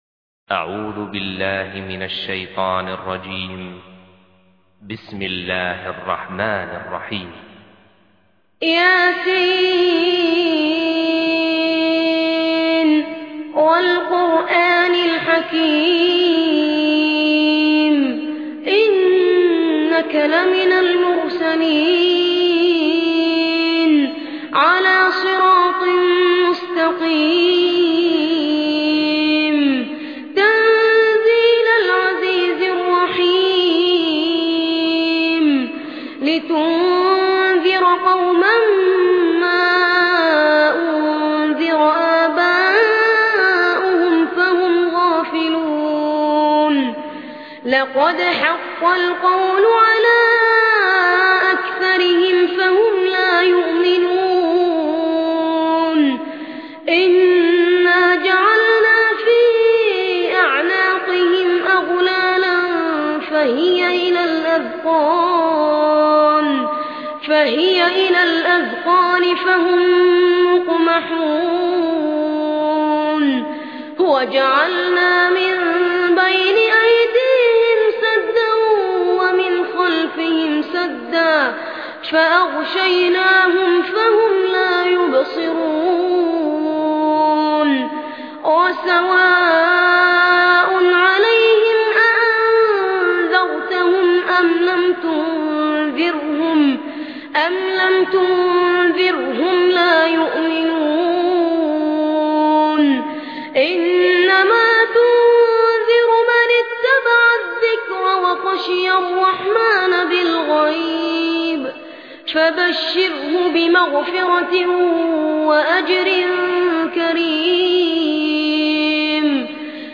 قرآن